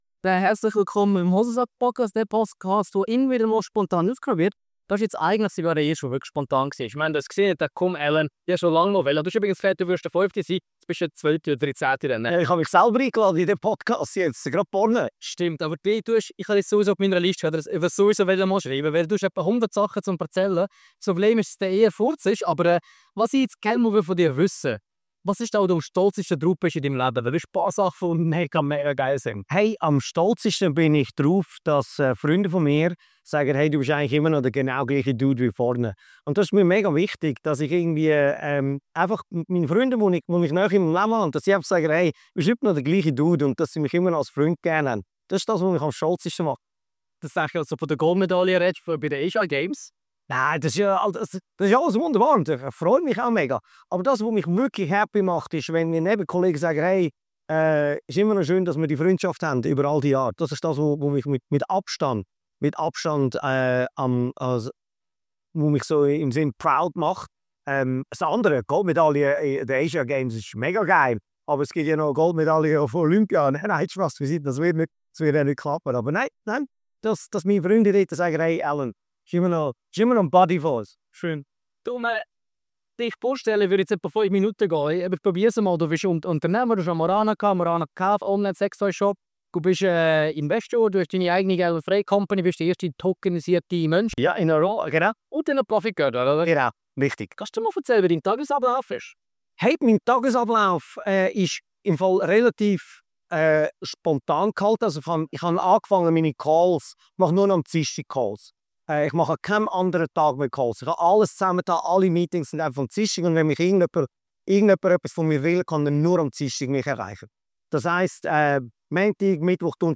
Er hat mit dem philippinischen Curling-Team die Asia Winter Games gewonnen – und will nun an die Olympischen Spiele. Diese Podcast-Folge wurde mitten am SEF aufgenommen – umgeben von 200 Menschen, ein echtes Hosensack-Erlebnis.